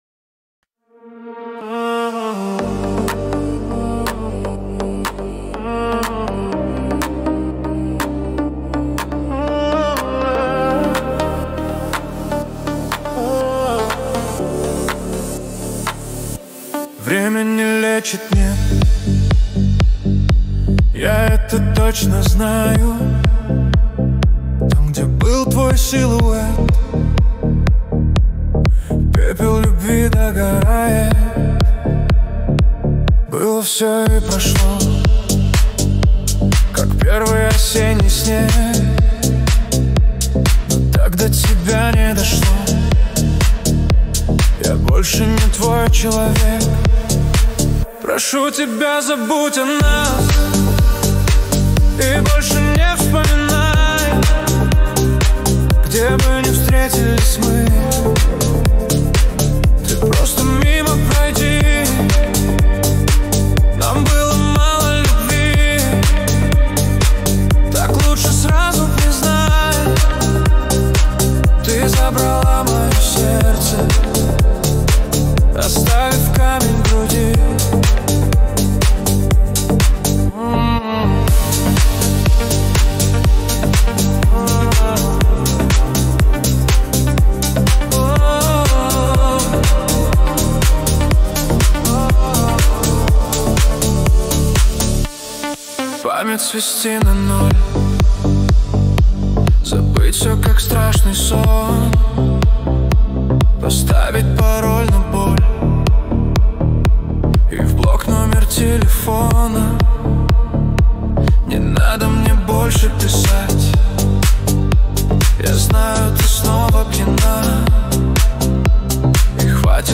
Песня ИИ